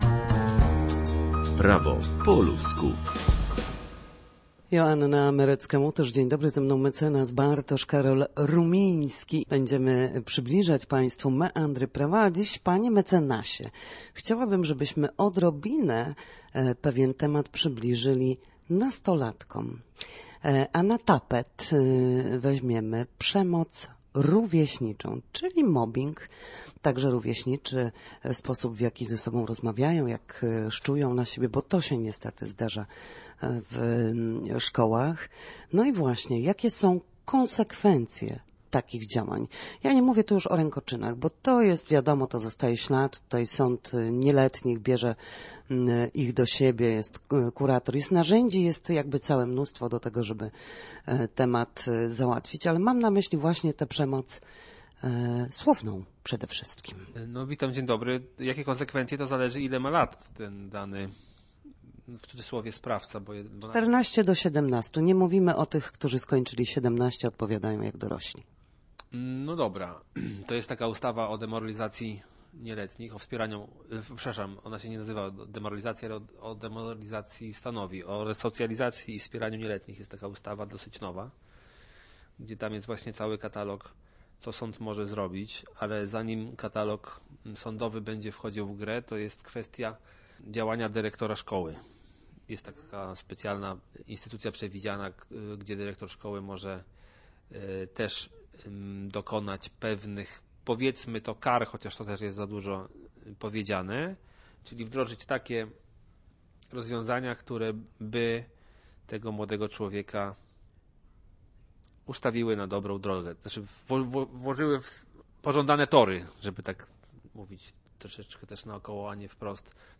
W naszym cyklu prawnym gościmy ekspertów,
W każdy wtorek o godzinie 13:40 na antenie Studia Słupsk przybliżamy państwu meandry prawa.